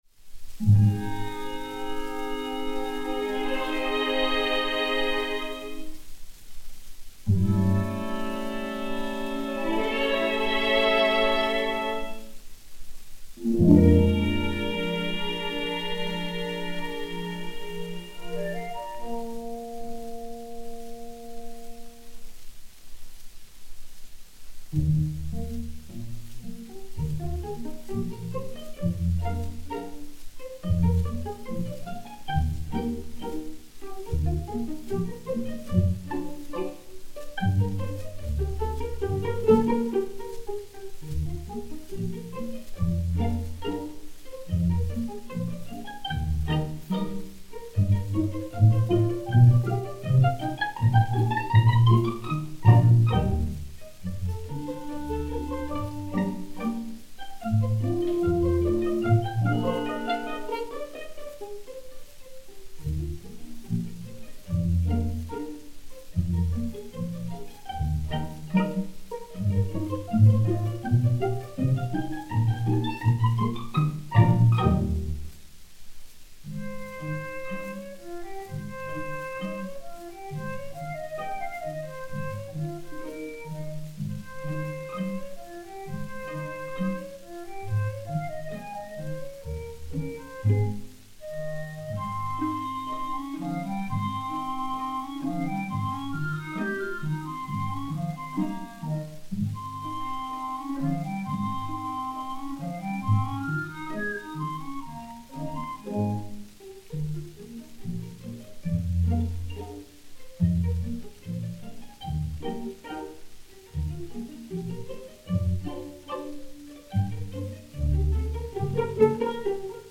Orchestre Symphonique dir.